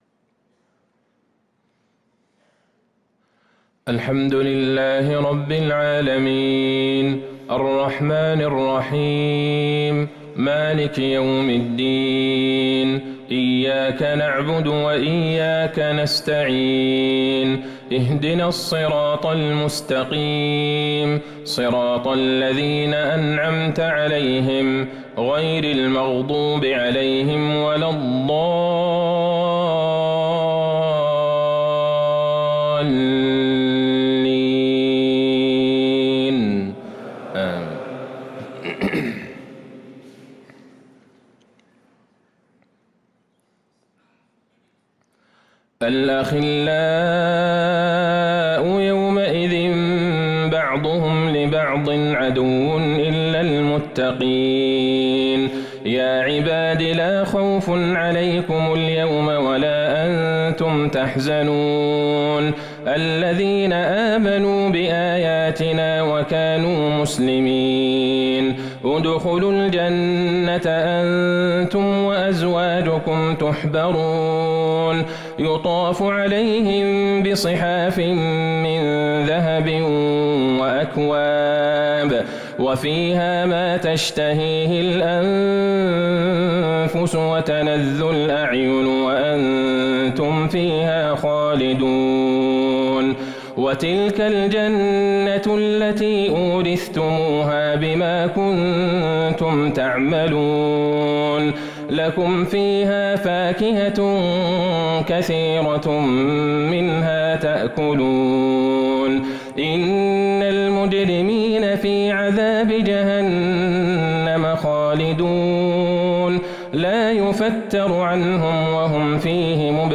صلاة العشاء للقارئ عبدالله البعيجان 28 جمادي الآخر 1444 هـ
تِلَاوَات الْحَرَمَيْن .